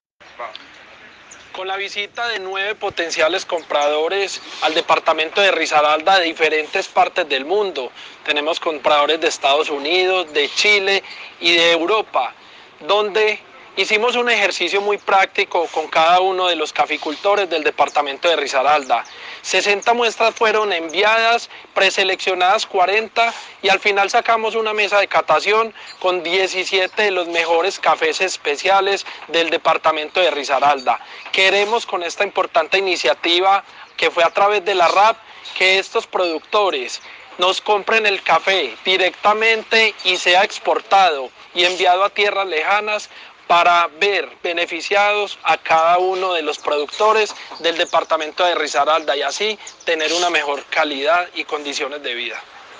Audio, Juan Carlos Toro Castellanos, secretario de Desarrollo Agropecuario.